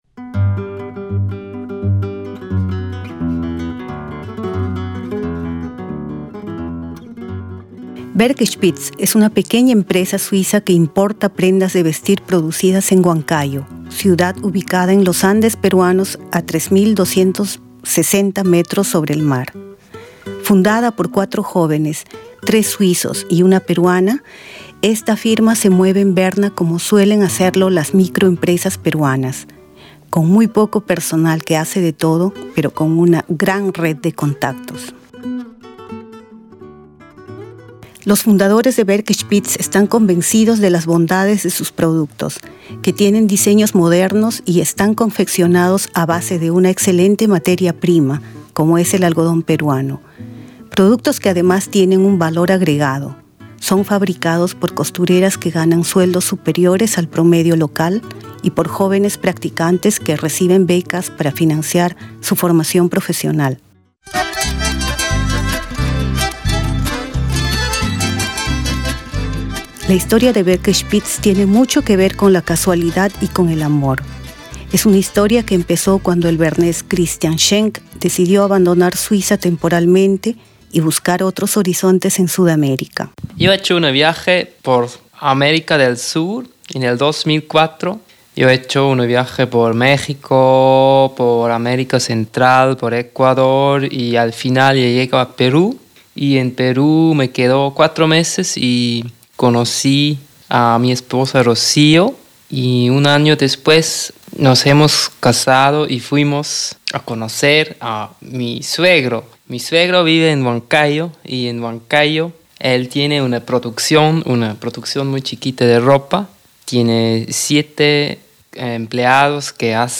El mercado suizo ha reaccionado positivamente ante estos textiles importados por un pequeño grupo de jóvenes empresarios suizos. Ellos refieren cómo surgió la idea de unir dos empresas, de Perú y de Suiza, países que, al parecer, tienen en común no sólo las montañas.